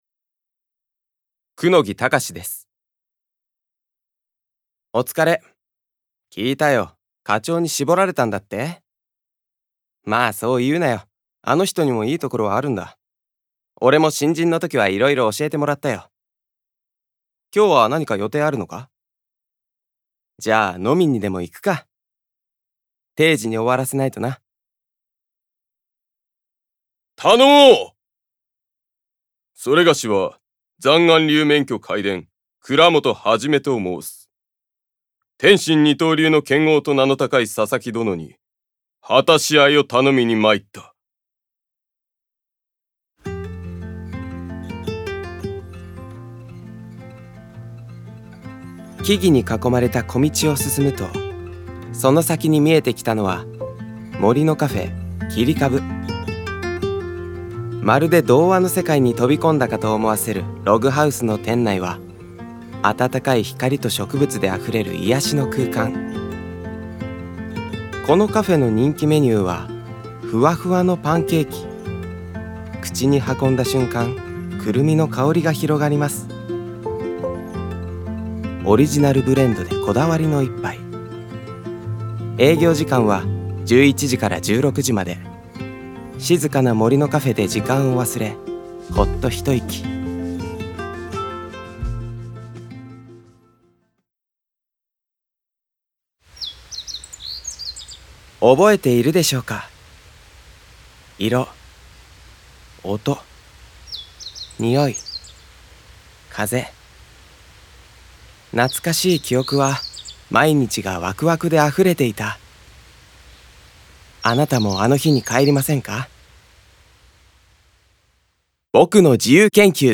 • 俳優
VOICE SAMPLE